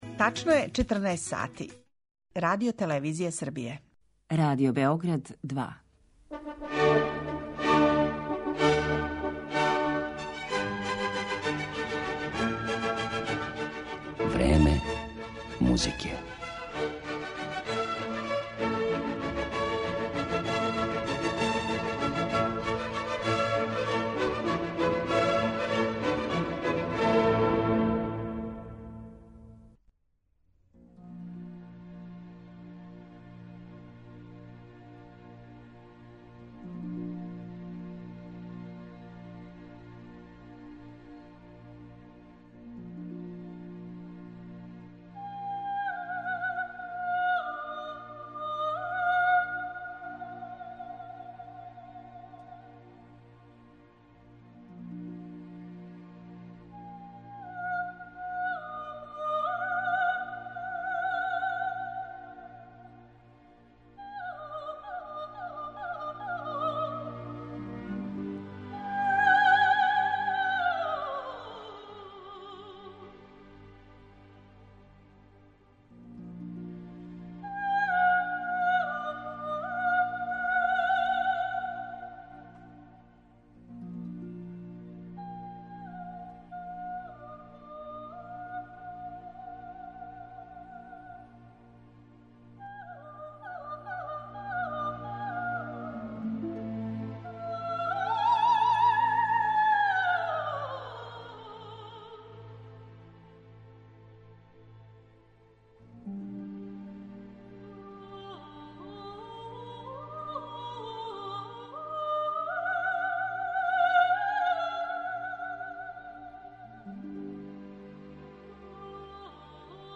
Вокализе
Емисија Време музике посвећена је вокализама, необичном облику намењеном певању без текста, у коме мелодију произносе само вокали.